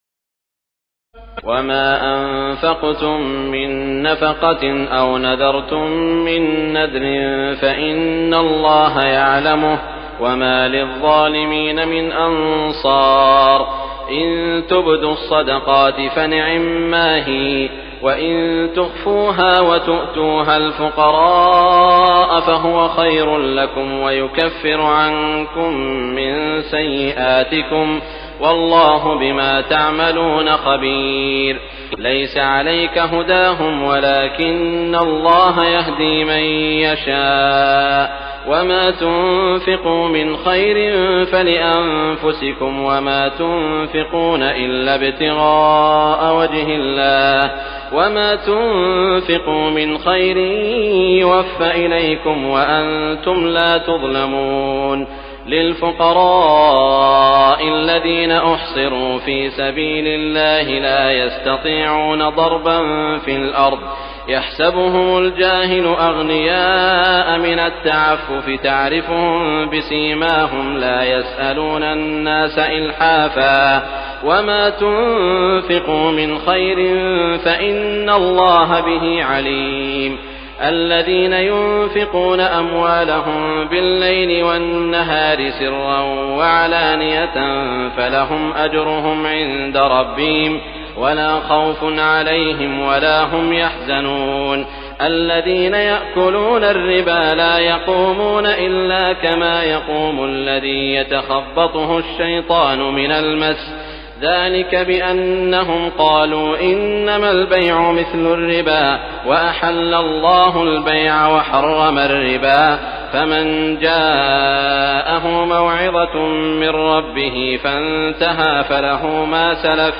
تراويح الليلة الرابعة رمضان 1418هـ من سورتي البقرة (270-286) وآل عمران (1-61) Taraweeh 4st night Ramadan 1418H from Surah Al-Baqara and Surah Aal-i-Imraan > تراويح الحرم المكي عام 1418 🕋 > التراويح - تلاوات الحرمين